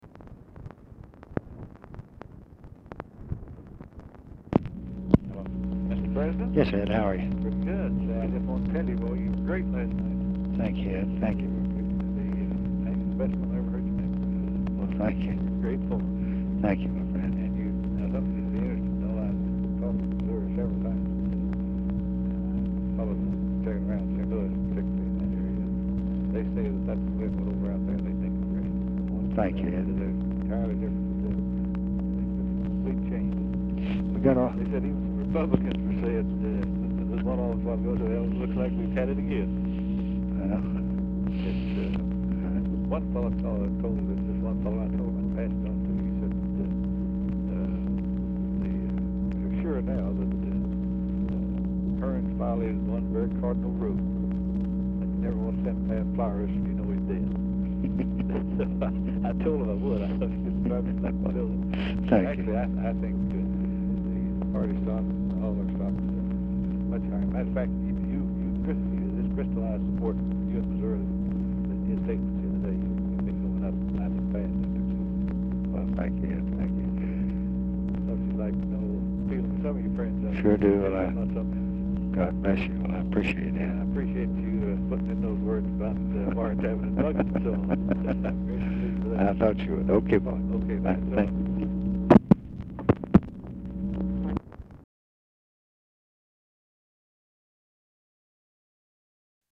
Title Telephone conversation # 11339, sound recording, LBJ and EDWARD LONG, 1/11/1967, 4:22PM Archivist General Note "SEN.
LONG IS DIFFICULT TO HEAR
Format Dictation belt